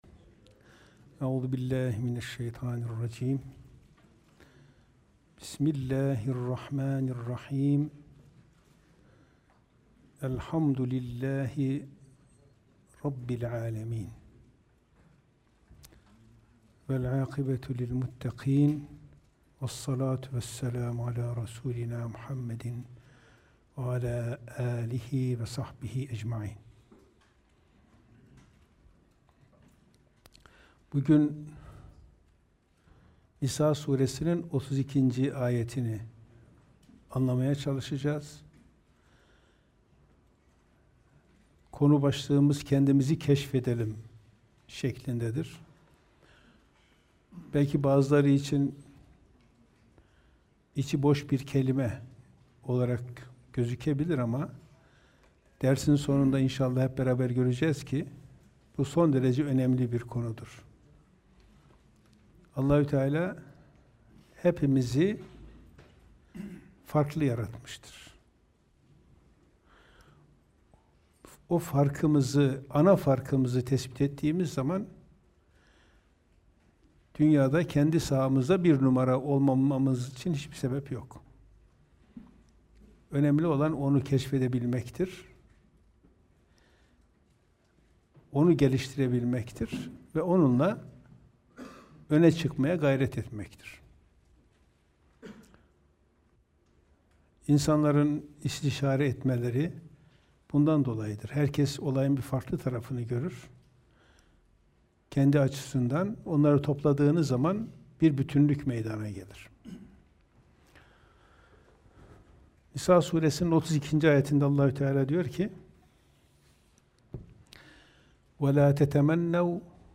Kur'an Sohbetleri